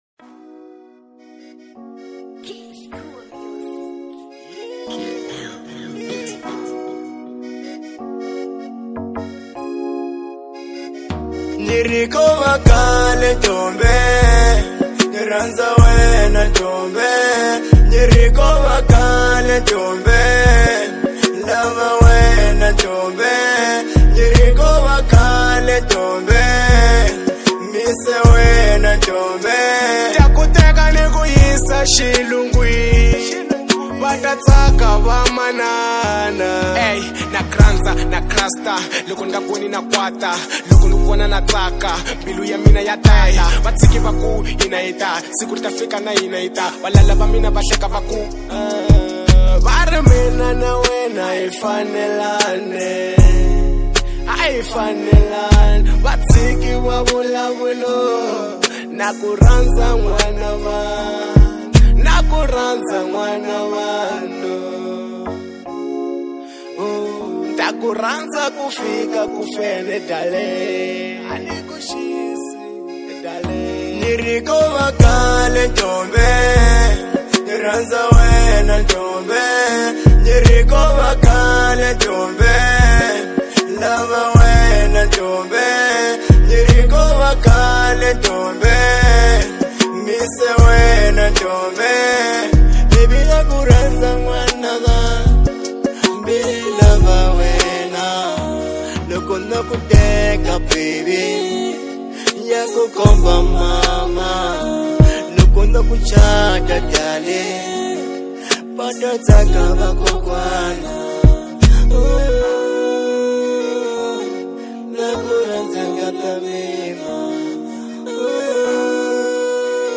Genre : Afro Pop